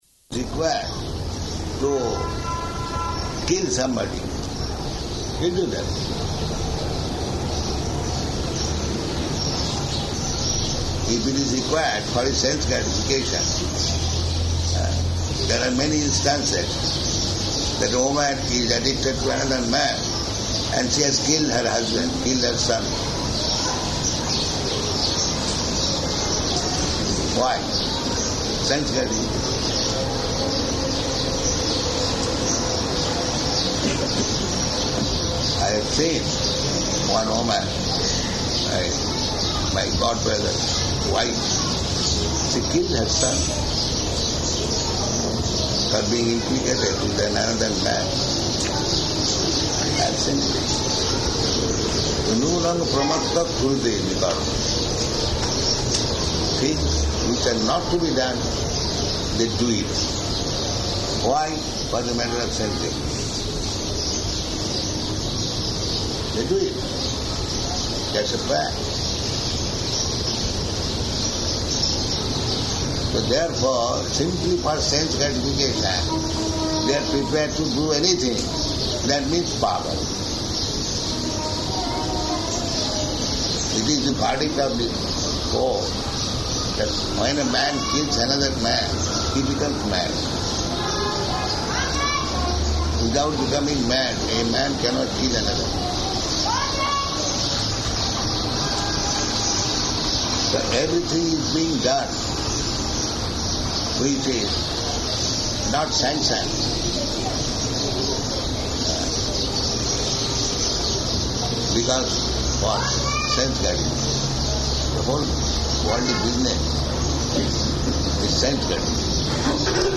Type: Conversation
Location: Delhi
[long Hindi conversation, with different guests arriving and leaving] Indian man (1): Apnāra praśna hai?